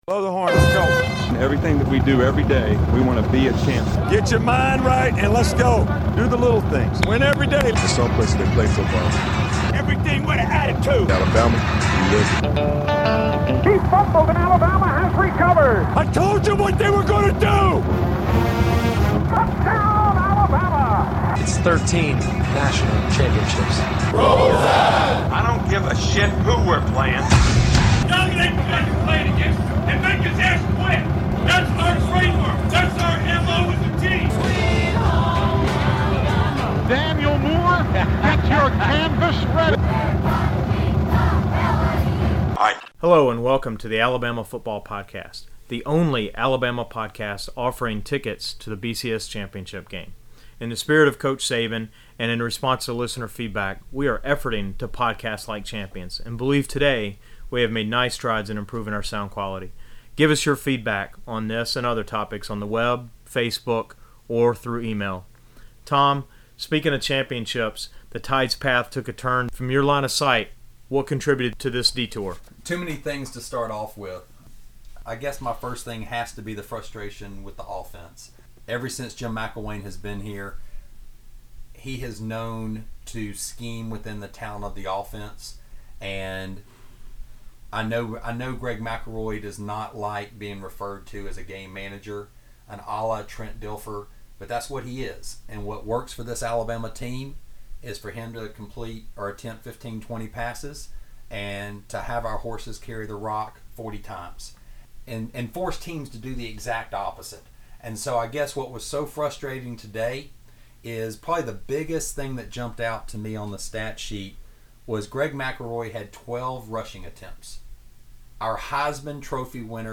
**Sorry for the delay… we were experiencing technical difficulties… we hope you enjoy and let us know what you think about the improved (we hope) sound quality!